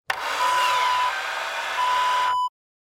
Car Side Mirror Adjust Wav Sound Effect #2
Description: The sound of adjusting automobile side view mirror
Properties: 48.000 kHz 24-bit Stereo
A beep sound is embedded in the audio preview file but it is not present in the high resolution downloadable wav file.
Keywords: car, auto, automobile, side, sideview, side-view, mirror, mirrors, adjust, adjusting, motor, servomotor, move, moving, movement
car-side-mirror-adjust-preview-2.mp3